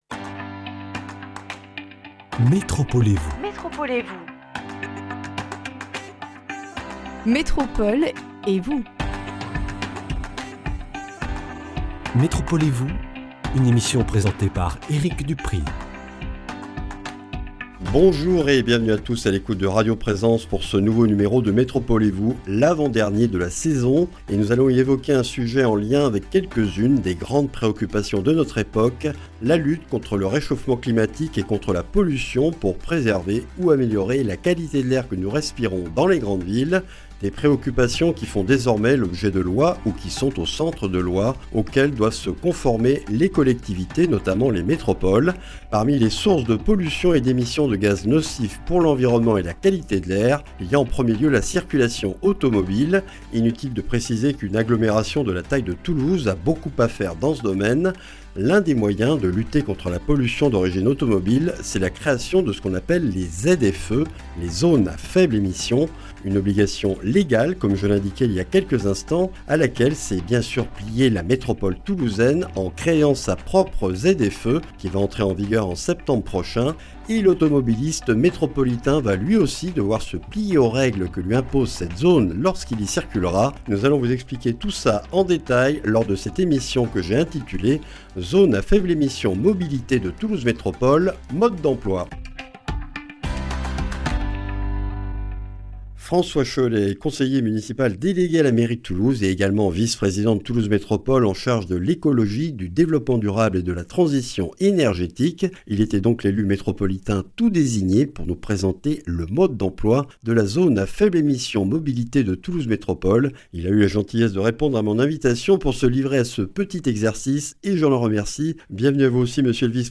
François Chollet, conseiller municipal délégué à la mairie de Toulouse, vice-président de Toulouse Métropole chargé de l’Écologie, du Développement durable et de la Transition énergétique, est l’invité de ce numéro. La Zone à faibles émissions- mobilités (ZFE-m) de Toulouse Métropole entre en vigueur en septembre prochain.